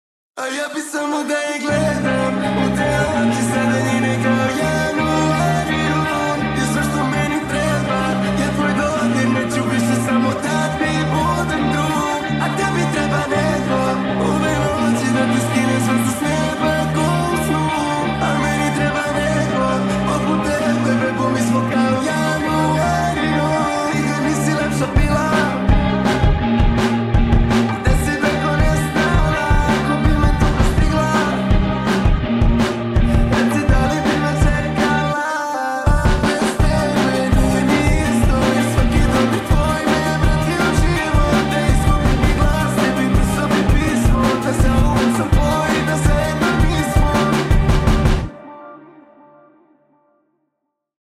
post rock